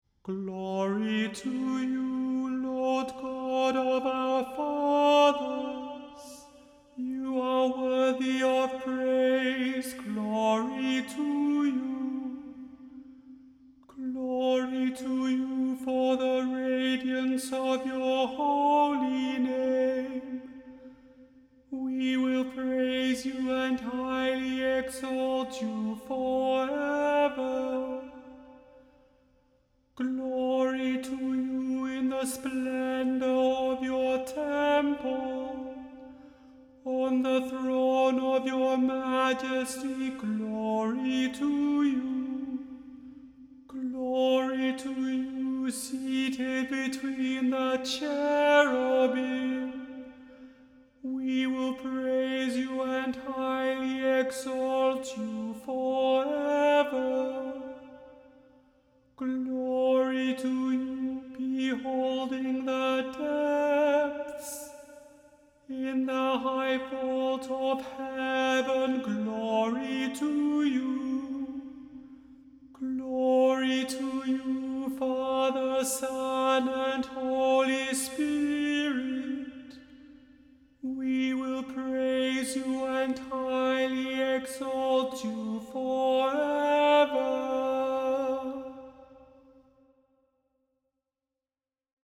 The Chant Project – Chant for Today (April 1) – Canticle 13